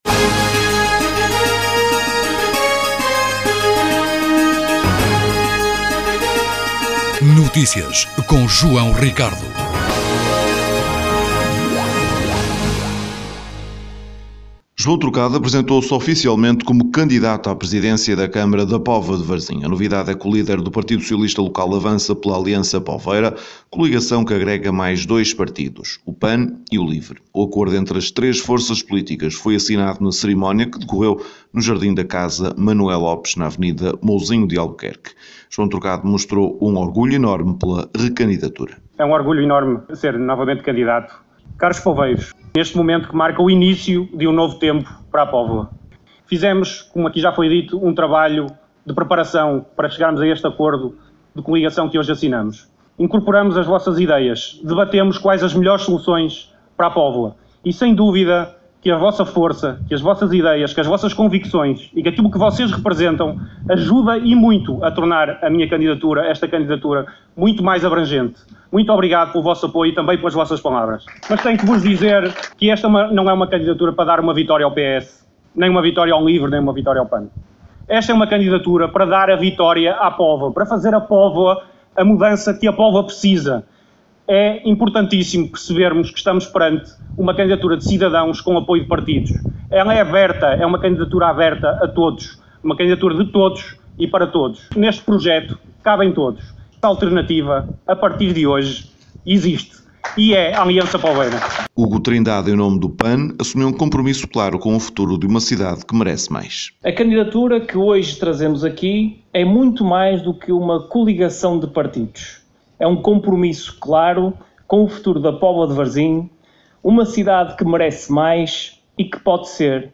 Foi no Jantar das Estrelas, uma iniciativa do Póvoa Andebol que juntou à mesa os amigos e patrocinadores do clube, a quem foi agradecido mais um ano de apoio, que decorreu a apresentação oficial de Carlos Resende. O novo treinador do PAC é uma clara aposta para elevar a fasquia na ambição do emblema poveiro.